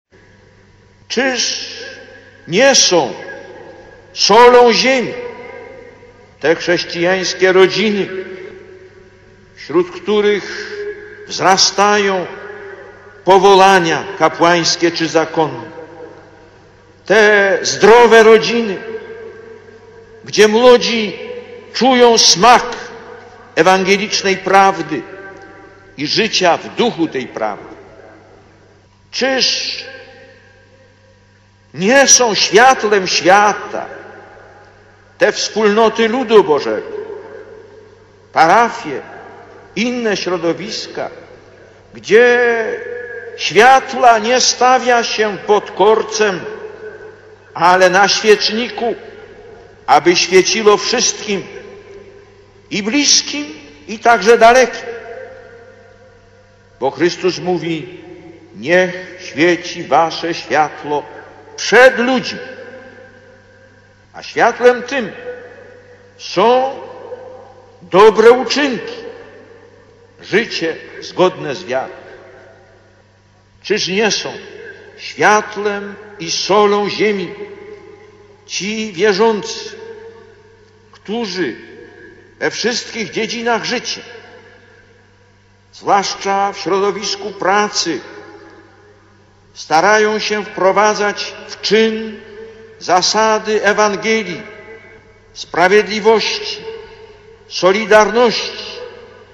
Lektor: Z homilii podczas Mszy świętej (Lublin, 9 czerwca 1987 – nagranie): „Dzisiaj jest pośrodku nas obecny Chrystus.